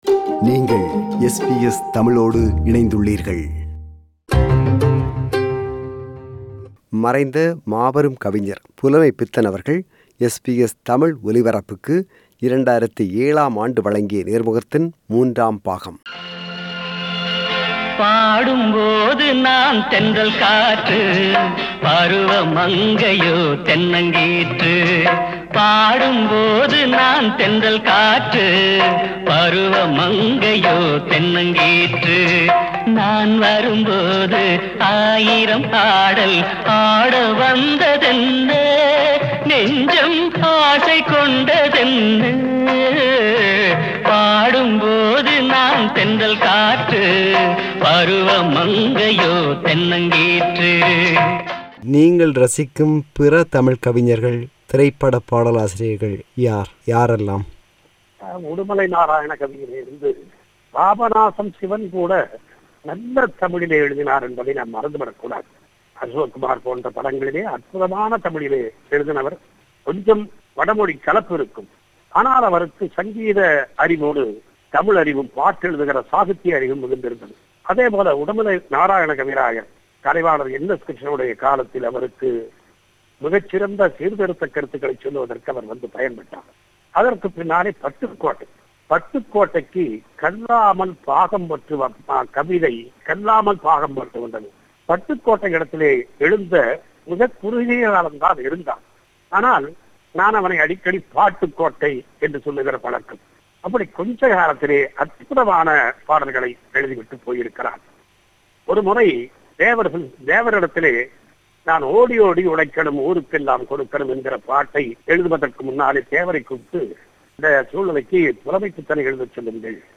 Interview with Poet Pulamaipithan – Part 3